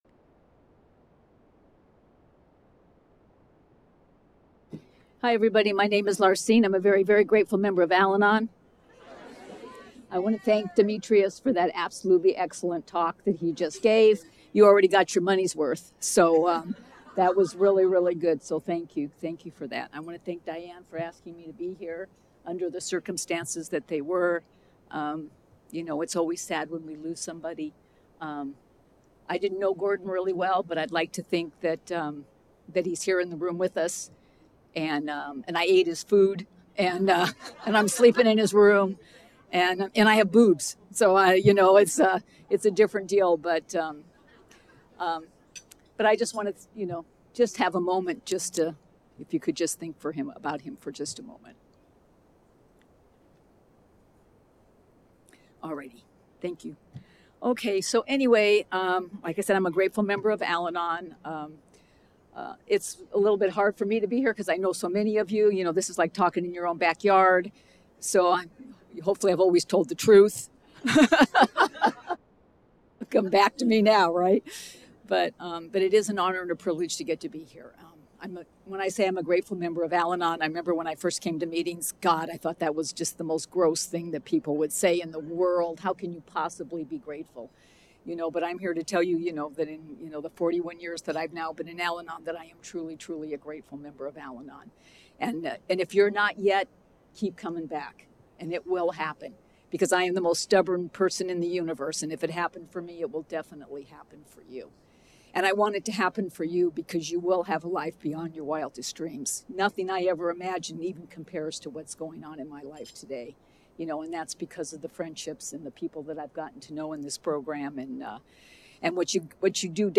46th So Cal Al-Anon Convention &#8211